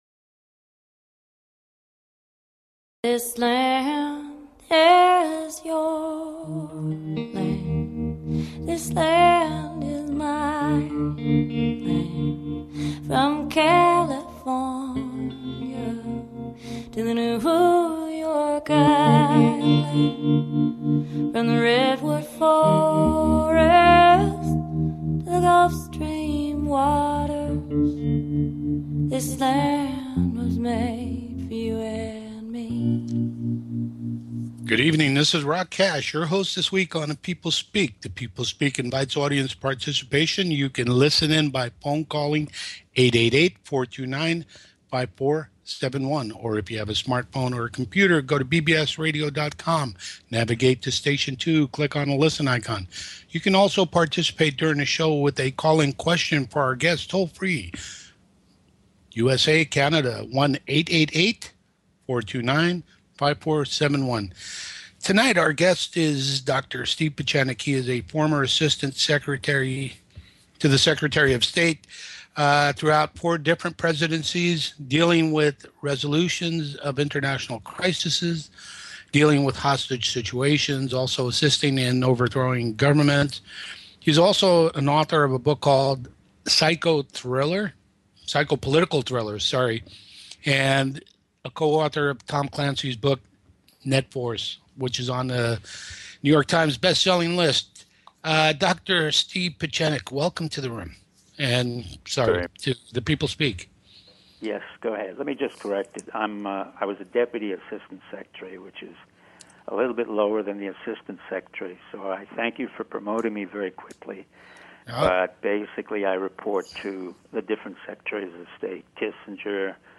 Guest, Steve Pieczenik